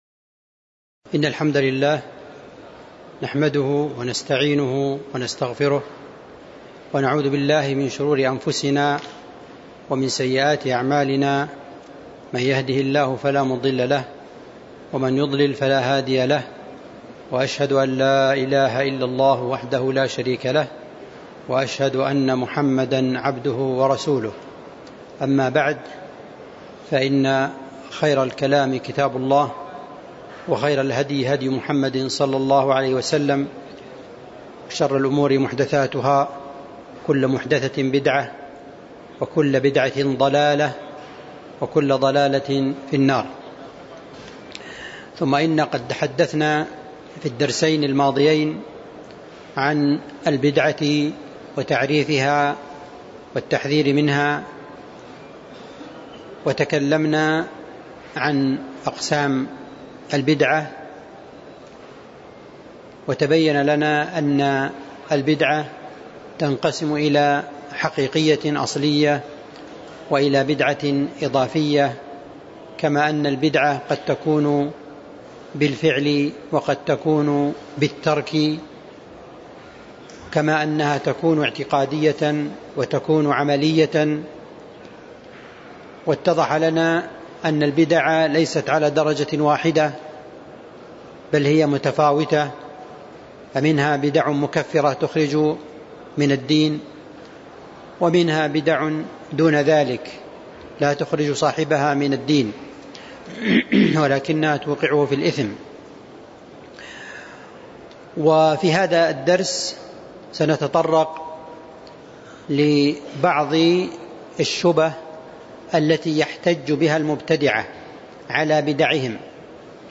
تاريخ النشر ٤ صفر ١٤٤٣ المكان: المسجد النبوي الشيخ